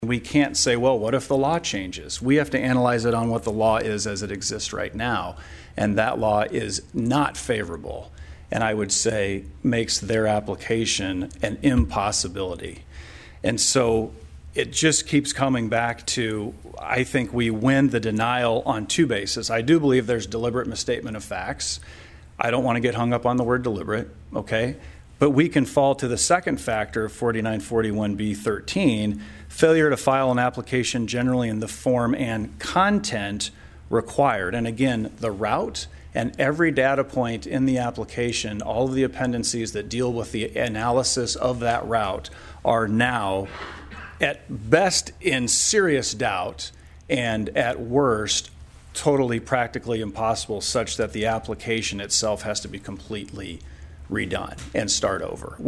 PIERRE, S.D.(HubCityRadio)- The South Dakota Public Utilities Commission held their meeting Thursday in Pierre.  One of the main topics was the status of the CO2 pipeline application made by Summit Carbon Solutions.